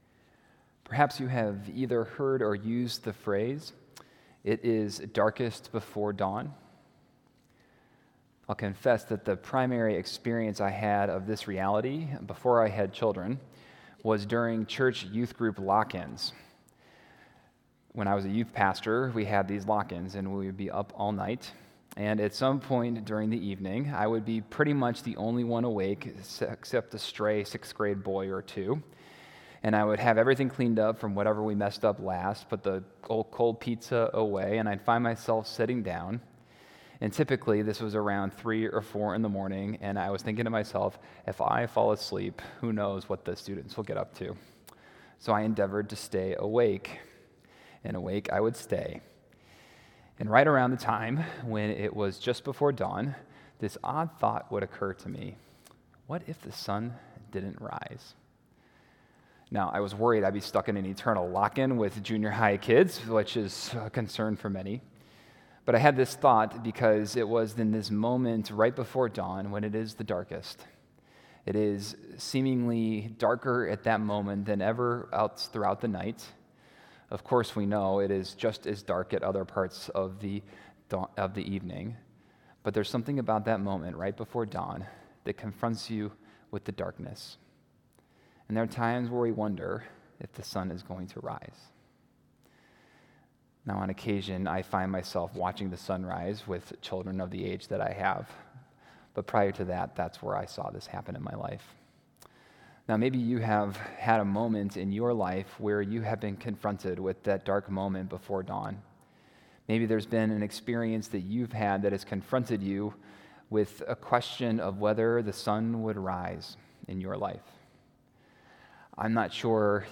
March 6, 2019 – Ash Wednesday Message – Park Ridge Presbyterian Church